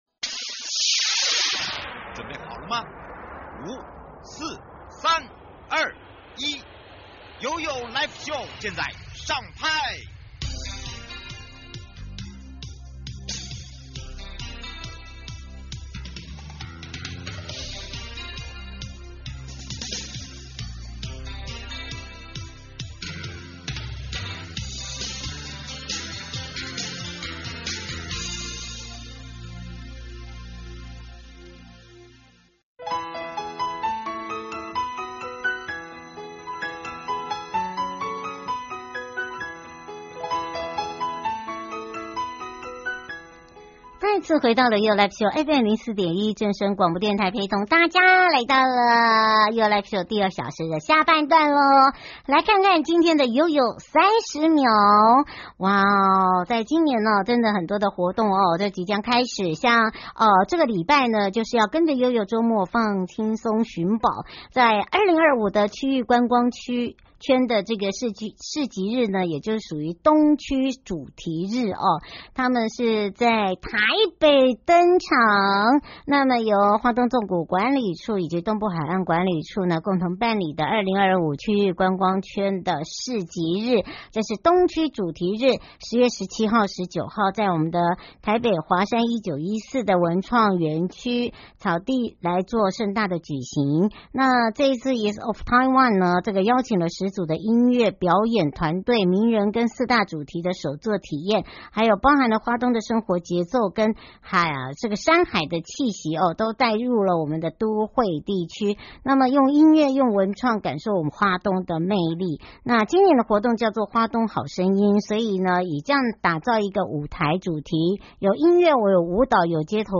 受訪者： 西拉雅管理處許主龍處長